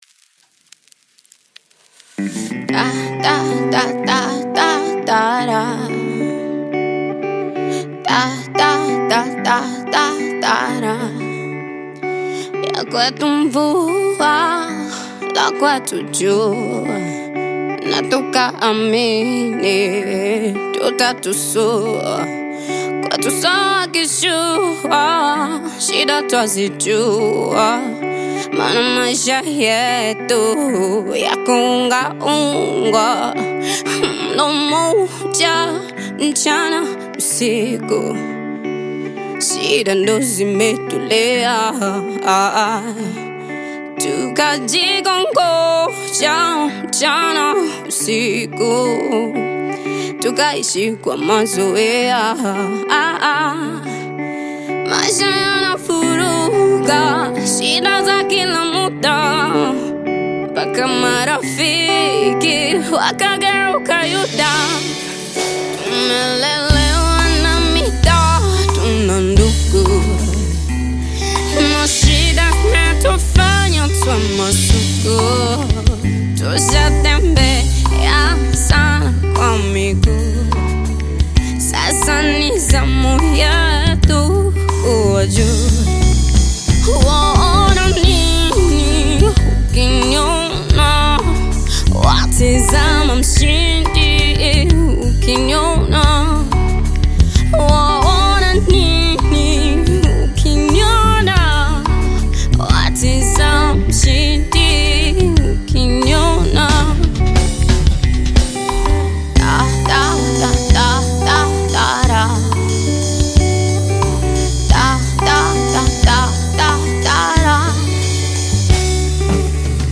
Tanzanian Bongo Flava
If you’re a fan of live music this one is for you.
Bongo Flava You may also like